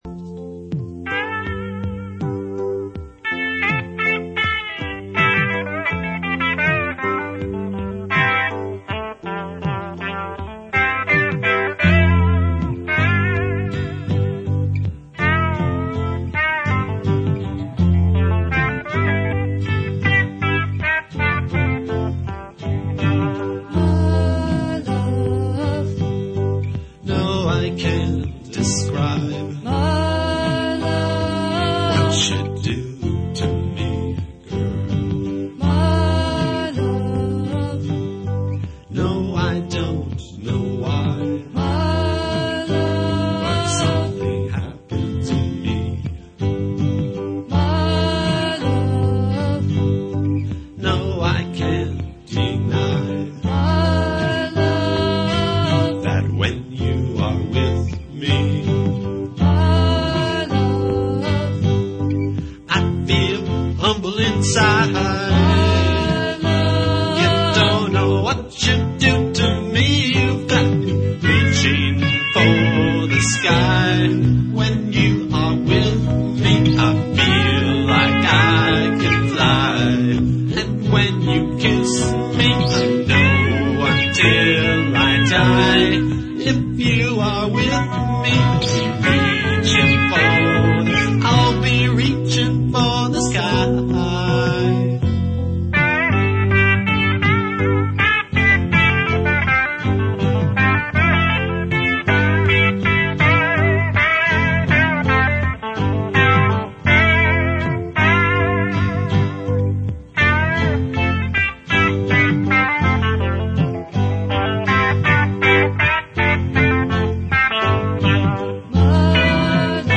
This song was written in 1985 and then recorded in a studio at San Francisco City College in 1986 and "released" (ok, given away to family and friends) on the album (ok, home made tape/CD), "Songs of Love (And Other Deviant Behavior)".
a strong Motown/R&B influence.